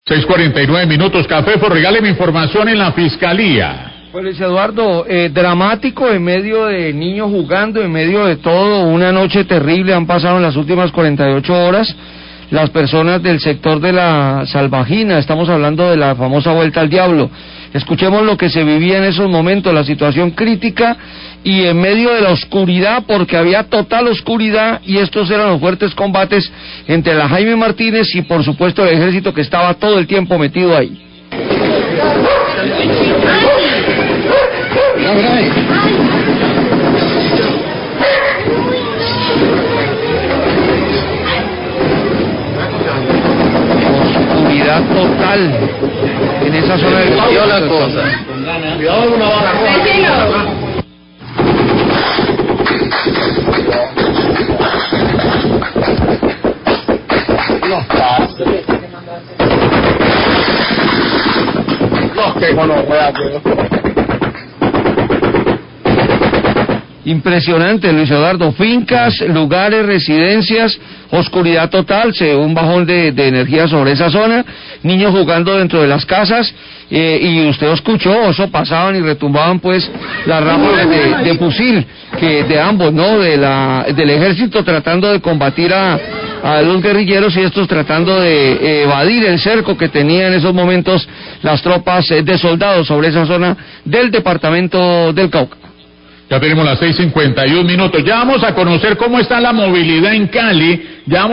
Radio
Audio de los momentos de temor que vivieron pobladores del área de La Salvajina por los fuertes enfrentamientos armados entre el Ejército Nacional y un grupo ilegal armado en emdio de la total oscuridad debido a un corte de energía.